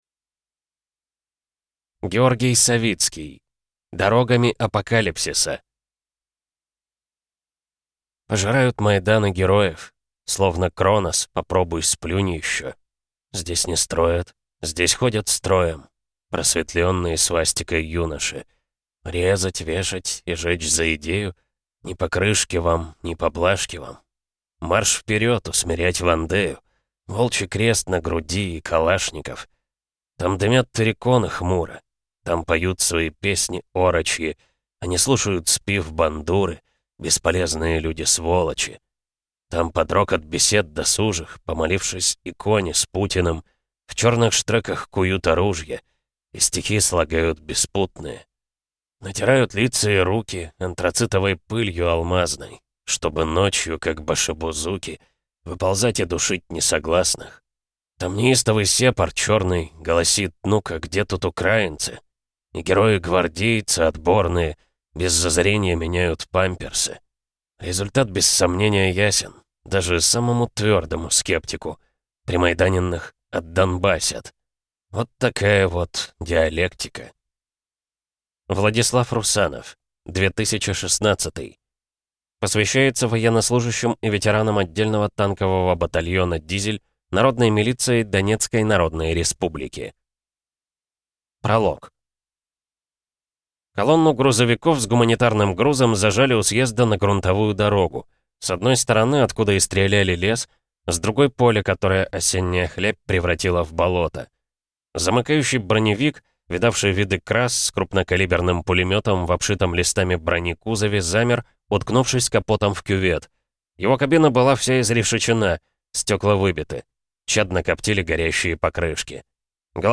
Аудиокнига Дорогами апокалипсиса | Библиотека аудиокниг